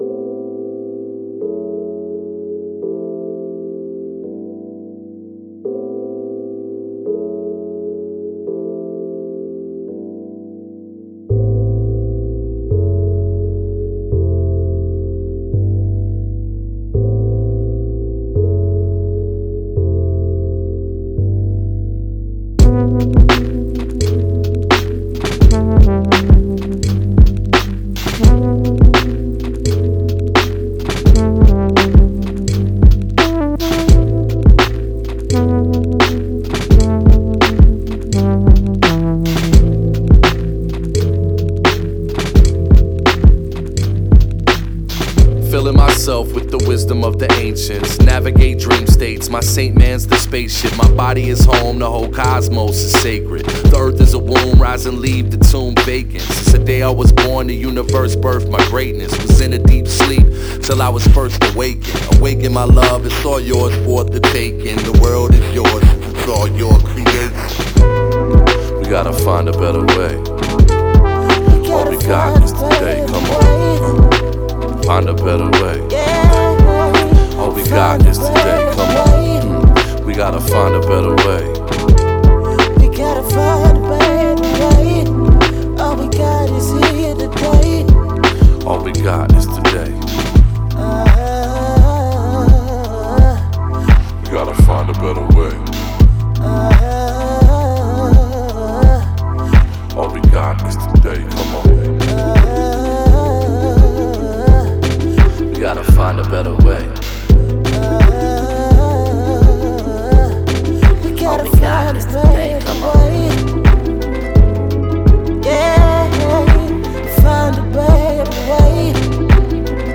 positive, upbeat and inspiring
gorgeous vocals to add character and soul to the track